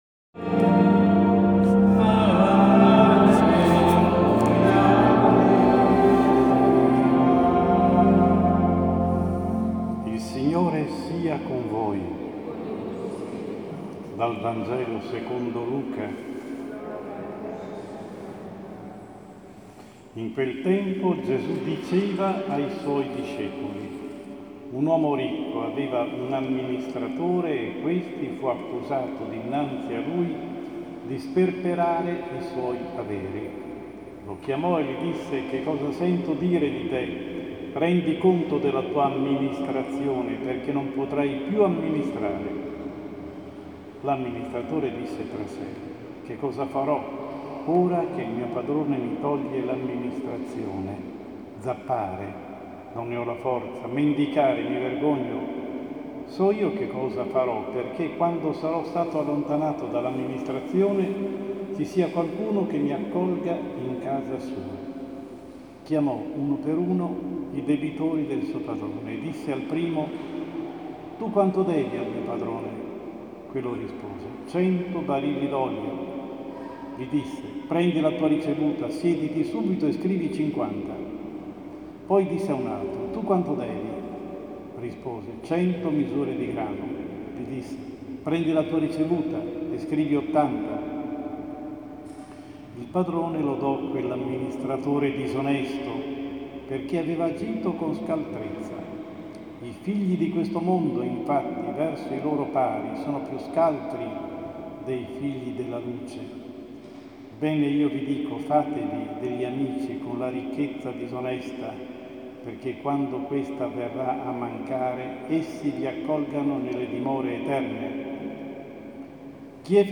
Omelia Domenica 25 tempo ordinario – 18 Settembre 2016